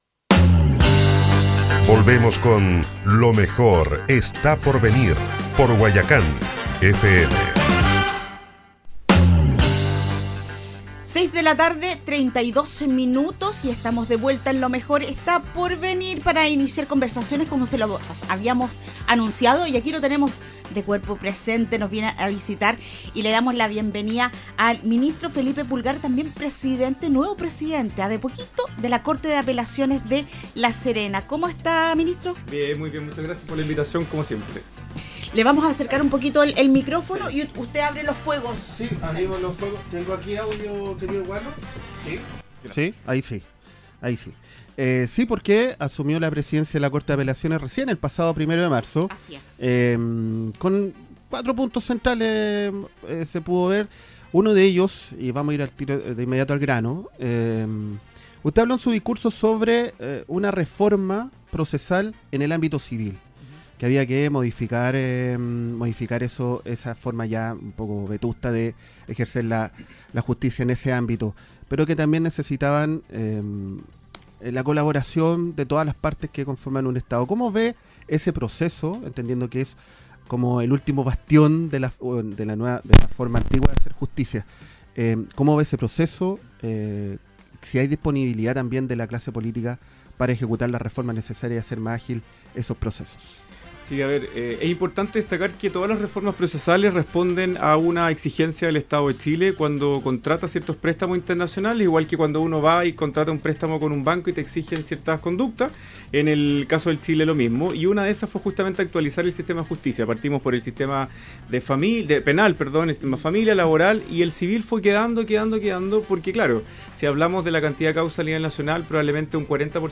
Escucha a continuación la entrevista completa al presidente de la Corte de Apelaciones de La Serena, ministro Felipe Pulgar: https